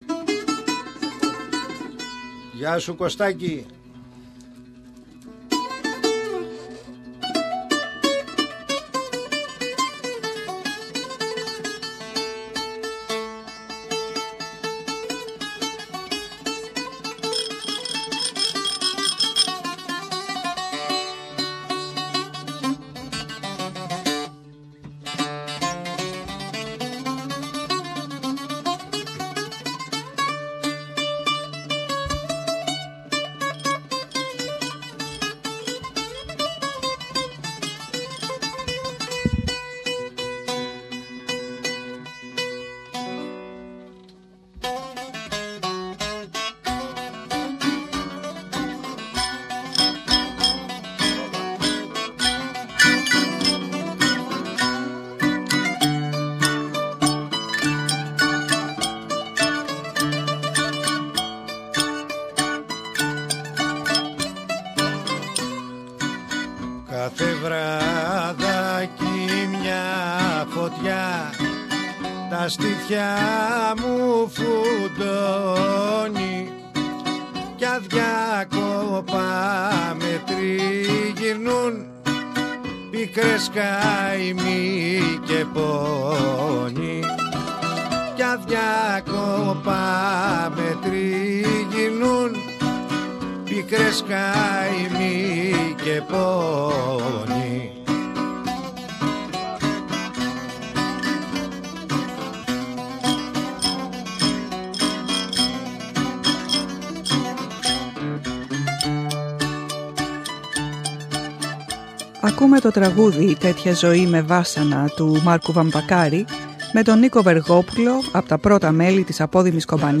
Με την ευκαιρία του φεστιβάλ ρεμπέτικου τραγουδιού φιλοξενήσαμε στο στούντιο της SBS μερικούς ρεμπέτες, άλλους νέους και άλλους πιο παλιούς...